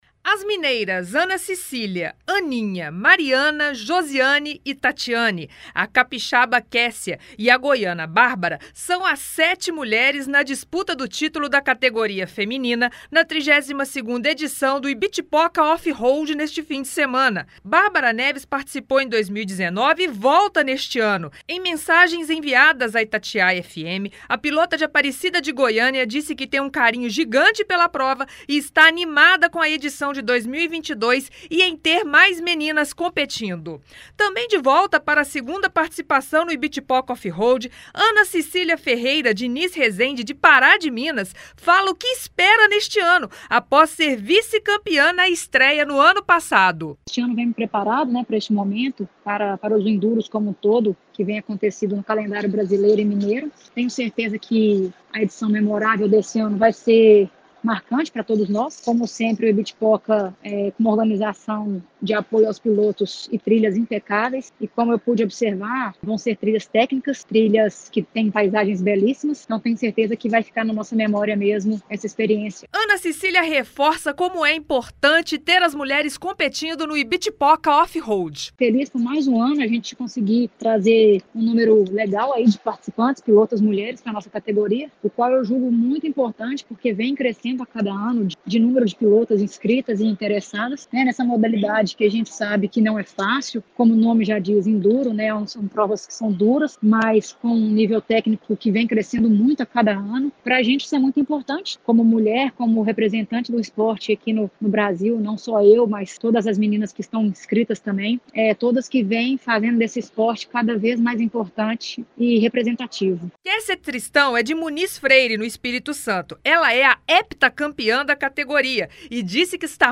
Nossa reportagem conversou com algumas das competidoras. Elas destacaram as expectativas para a prova na da categoria feminina e também a importância de ter as garotas no mundo do enduro e do off road.